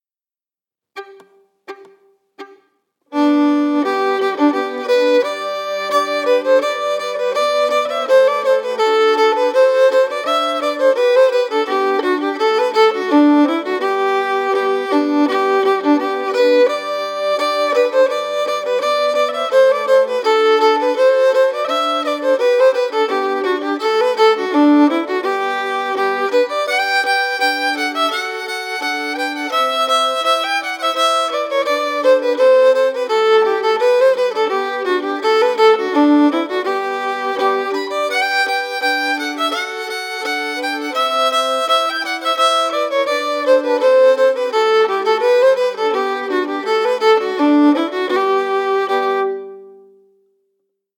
Key: G
Form: Gånglåt or Walking tune
Source: Traditional
Appelbo-Ganglat-audio-melody.mp3